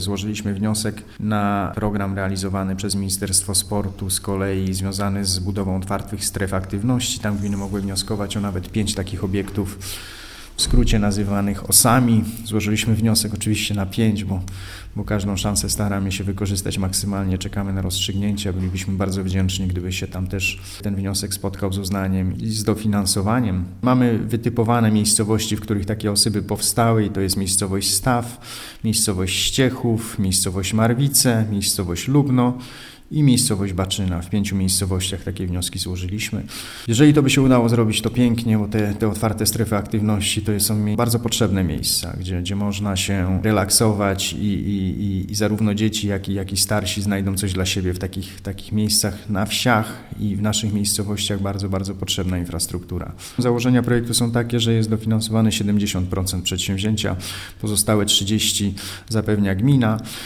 Gmina złożyła bowiem do Ministerstwa Sportu wniosek o dofinansowanie ich budowy w miejscowościach Staw, Ściechów, Marwice, Lubno i Baczyna. 70 procent kosztów budowy Otwartych Stref Aktywności pokrywa Ministerstwo Sportu, 30 procent gmina. – mówi wójt Artur Terlecki: